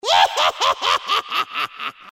Звук смеха ведьмы
Издевательский смех ведьмы средних лет
izdevatelskij-smeh-vedbmy-srednih-let.mp3